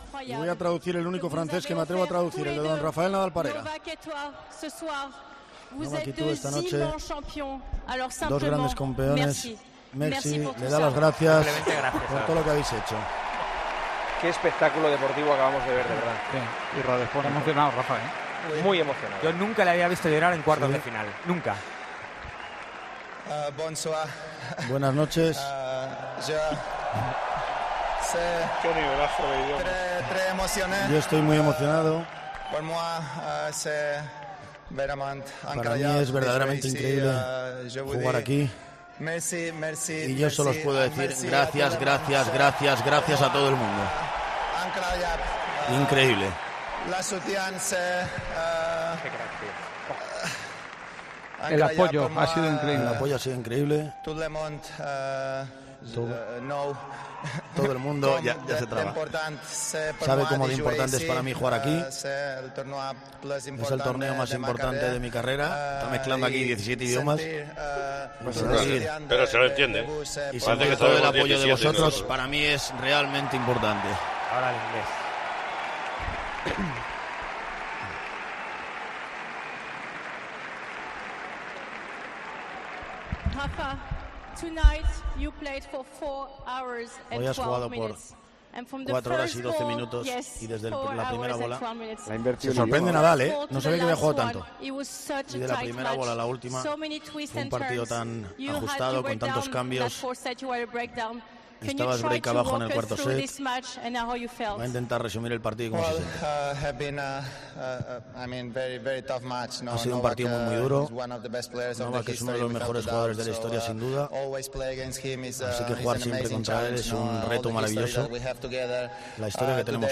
El tenista español habló tras derrotar a Djokovic 6-2, 6-4, 2-6 y 6-7 y pasar a las semifinales de Roland Garros en un gran partido.